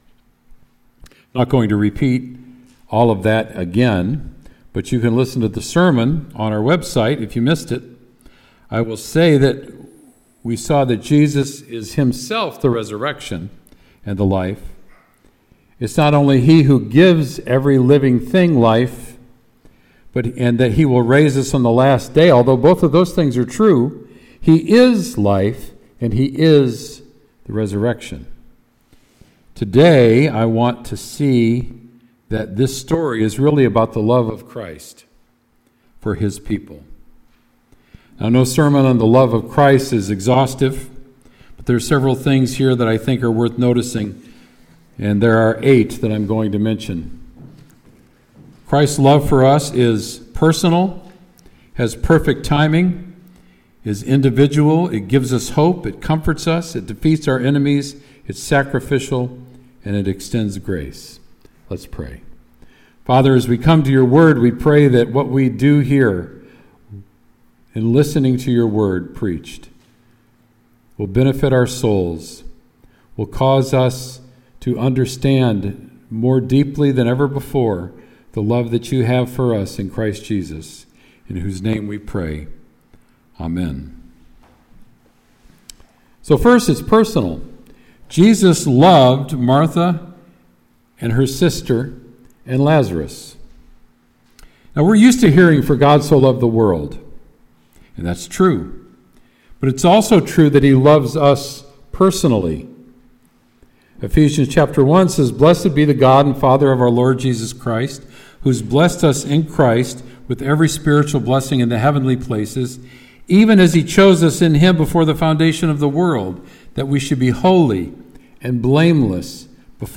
Sermon “Jesus Raises Lazarus”